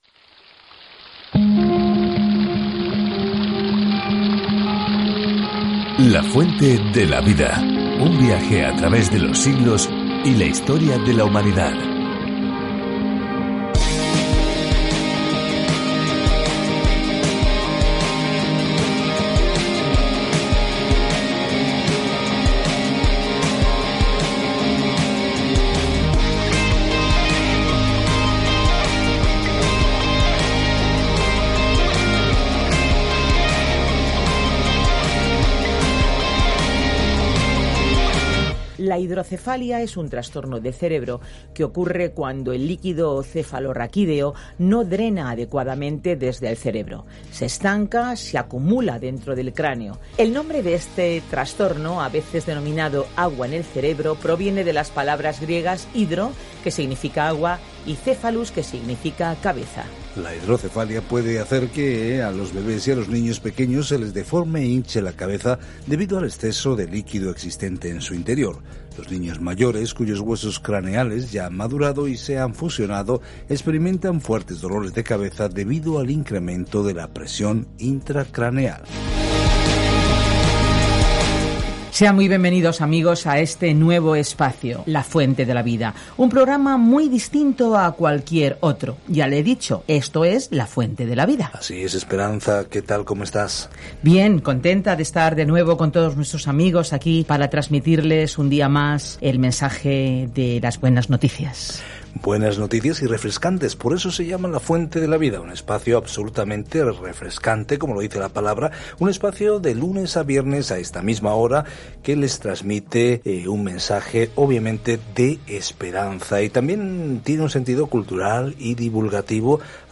Escritura AMÓS 5:4-17 Día 9 Iniciar plan Día 11 Acerca de este Plan Amós, un predicador rural, va a la gran ciudad y condena sus conductas pecaminosas, diciendo que todos somos responsables ante Dios según la luz que Él nos ha dado. Viaja diariamente a través de Amós mientras escuchas el estudio en audio y lees versículos seleccionados de la palabra de Dios.